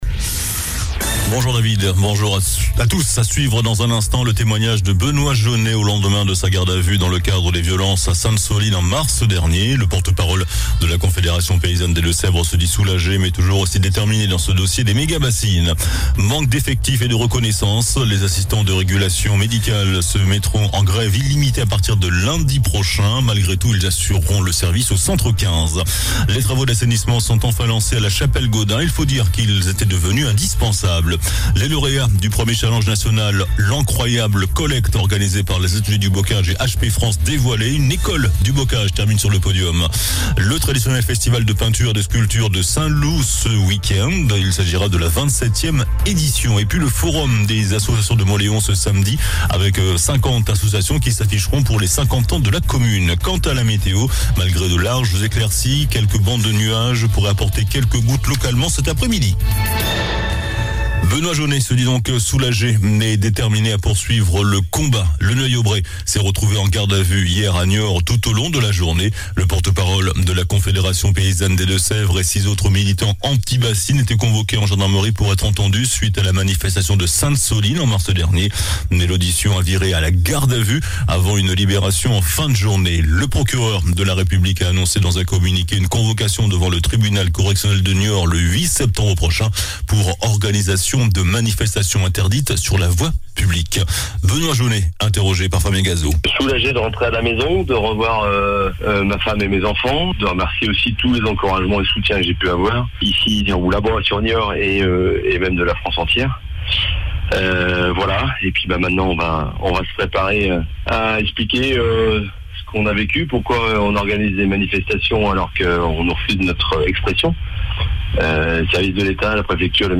JOURNAL DU JEUDI 29 JUIN ( MIDI )